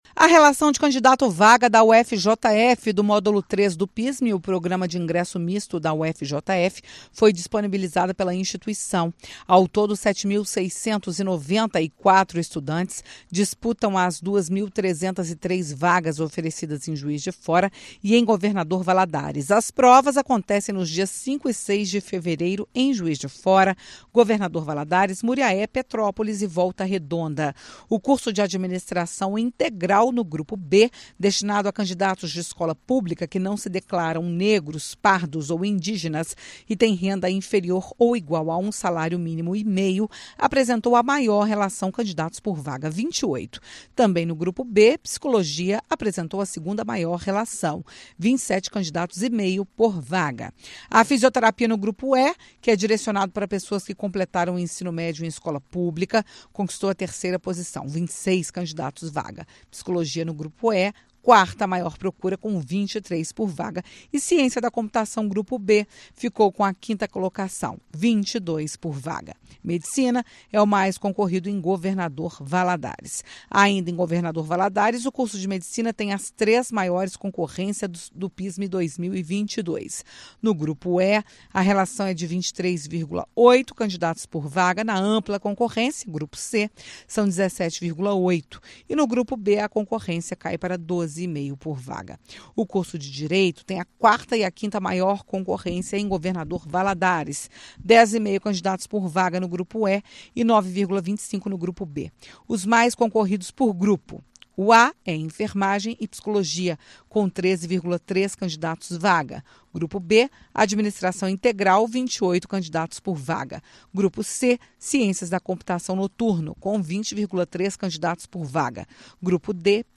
No campus Juiz de Fora, a maior concorrência é para o curso de Administração. Clique e ouça os detalhes na reportagem da FM Itatiaia.